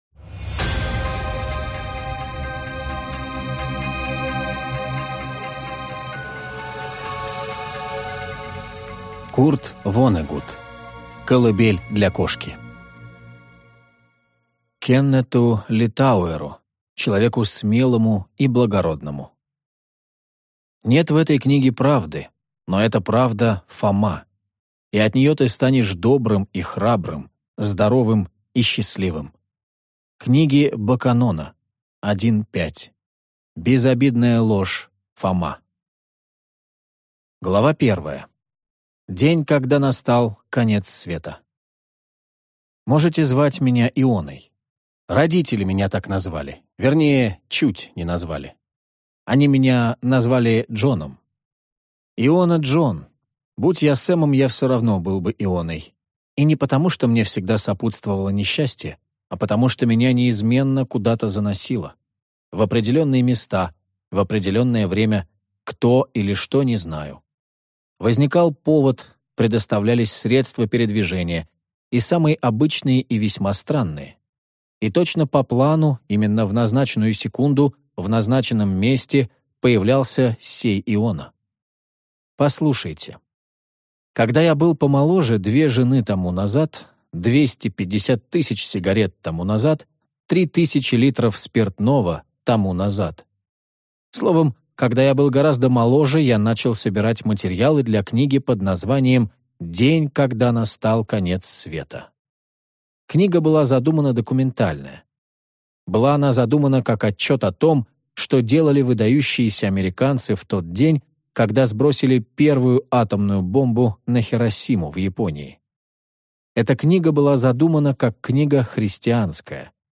Аудиокнига Колыбель для кошки | Библиотека аудиокниг